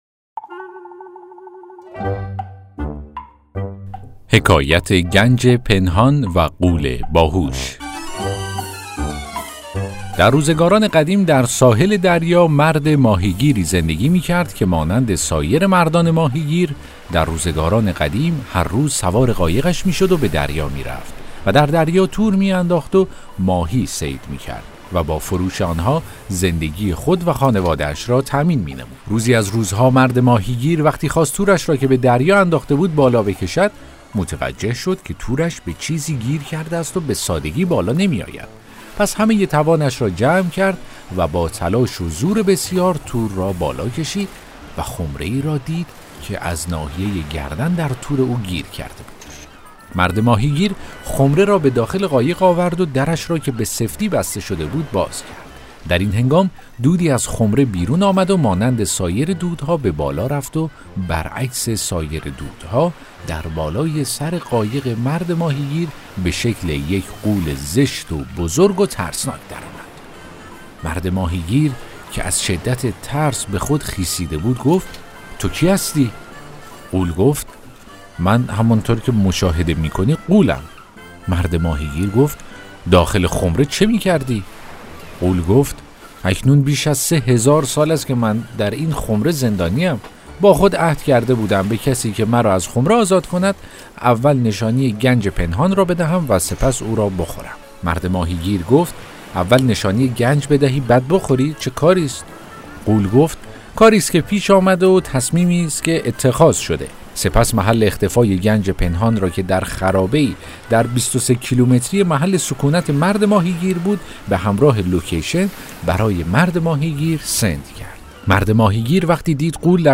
داستان صوتی: حکایت گنج پنهان و غول باهوش